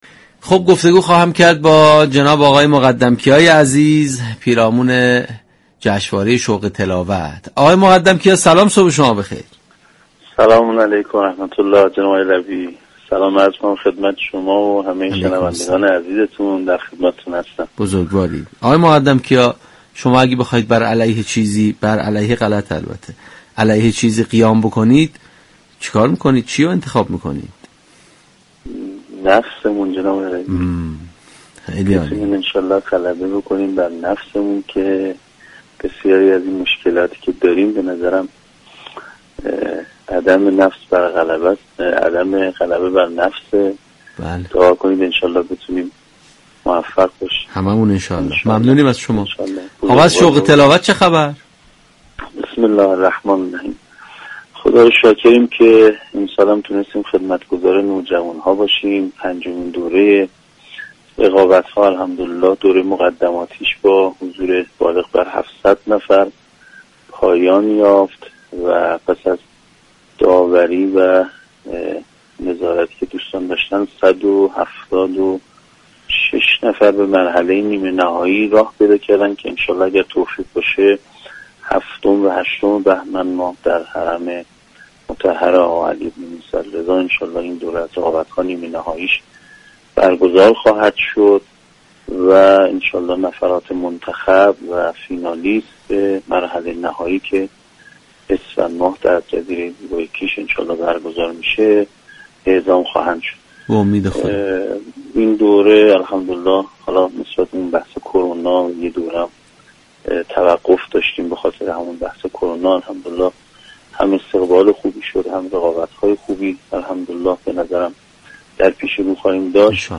در گفت‌وگو با برنامه تسنیم رادیو قرآن